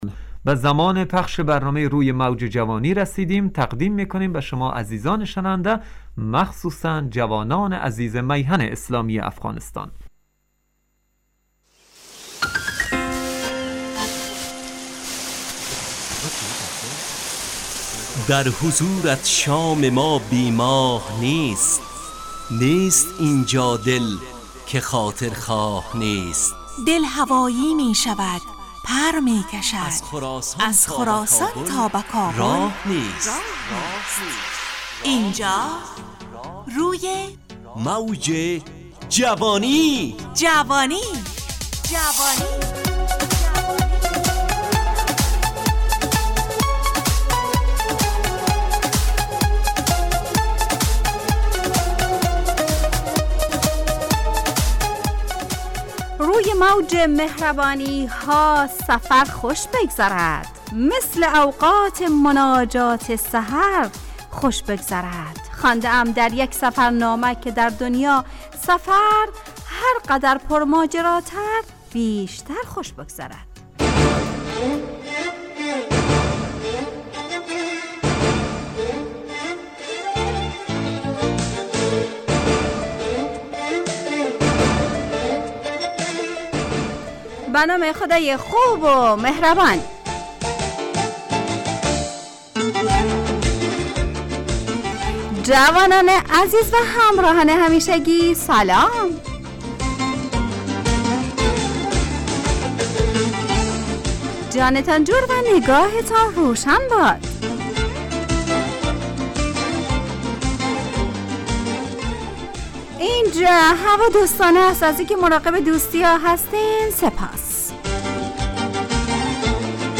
همراه با ترانه و موسیقی مدت برنامه 55 دقیقه . بحث محوری این هفته (مراقبت ) تهیه کننده